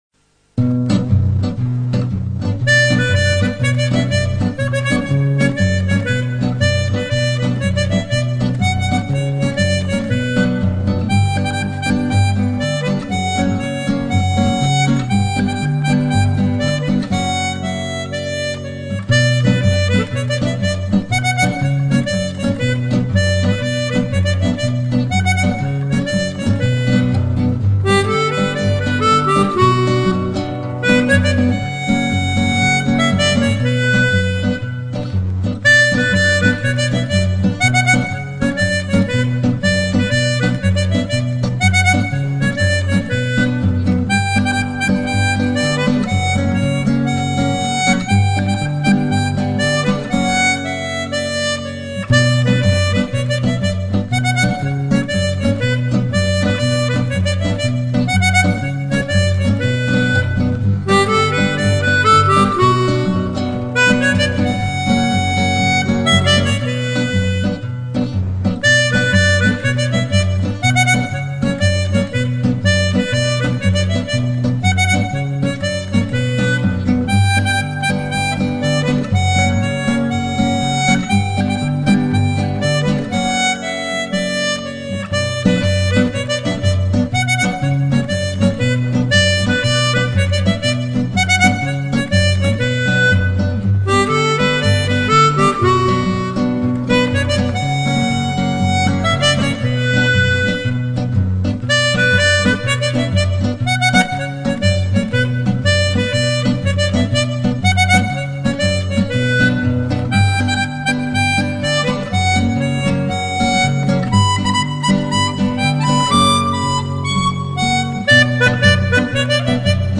Brassens - Fil ouvert - Harmonica
Encore quelques faiblesses dans l'interprétation...
En plus pas facile de tenir le rythme ...